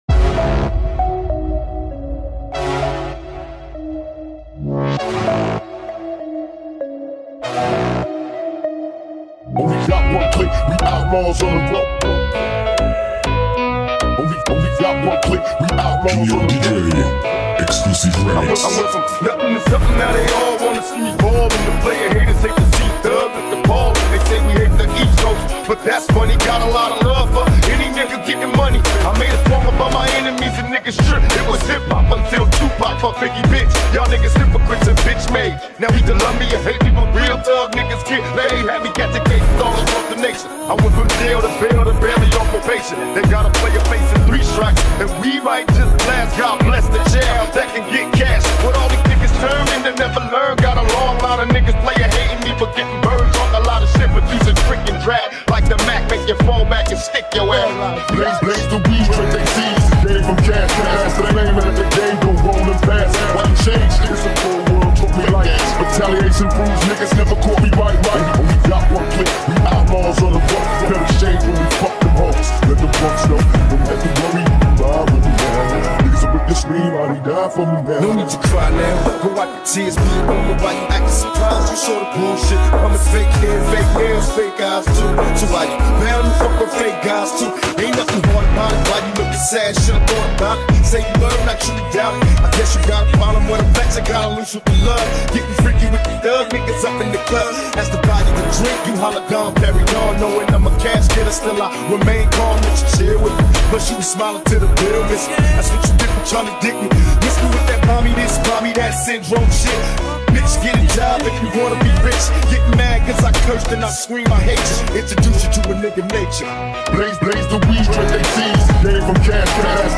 TikTok remix